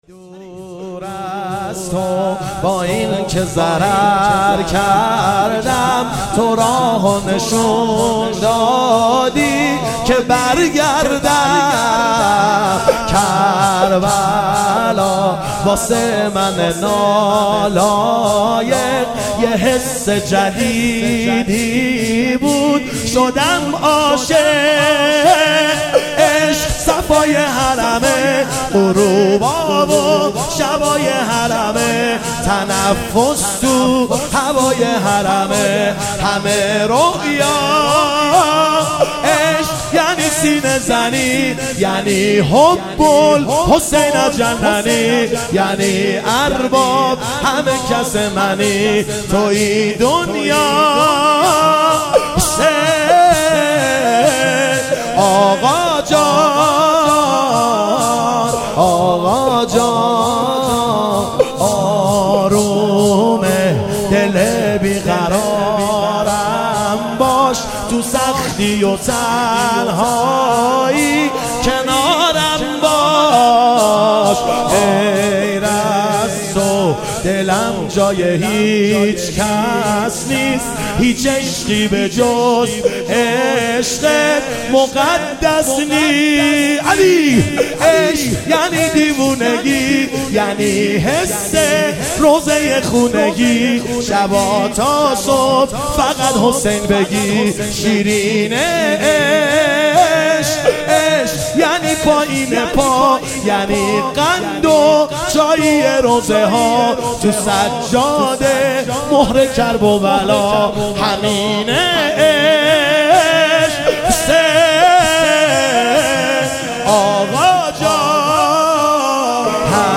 موکب الشهدا ساوجبلاغ |اربعین 1442